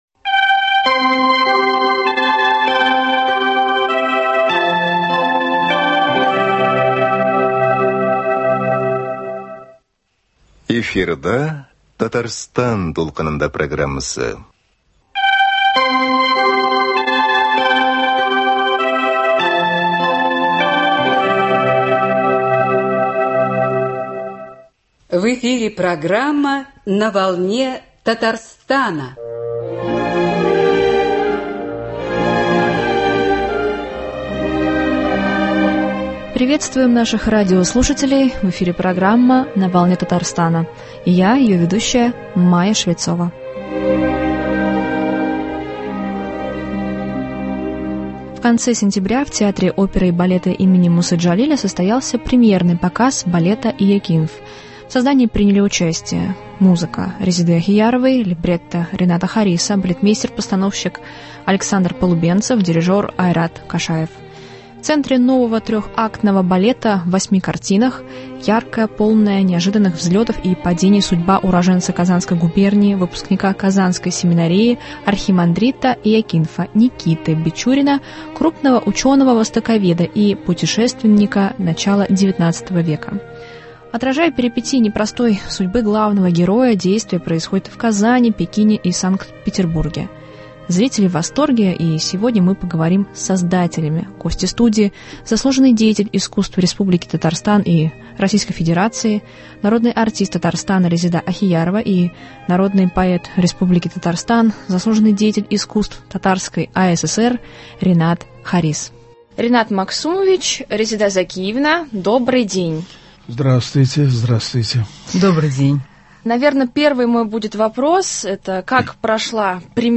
Балет «Иякинф». Гости студии